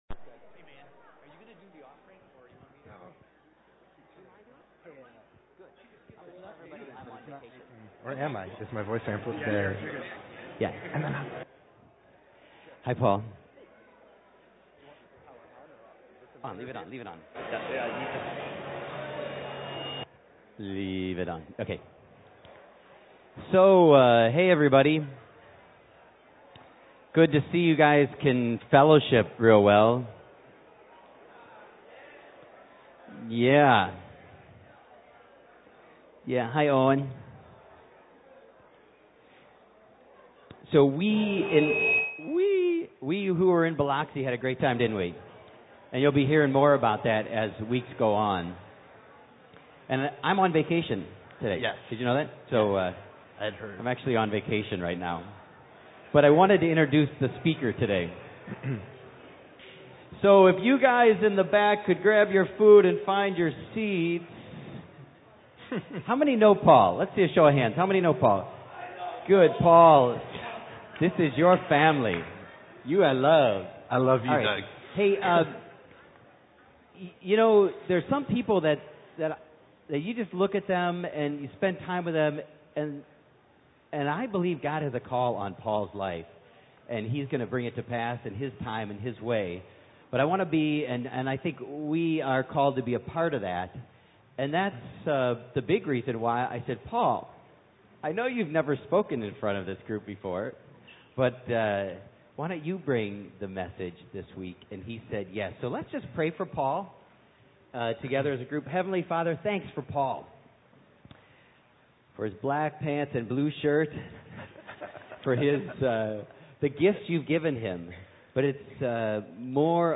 Find a previous sermon | Subscribe to COH's Sermon Podcast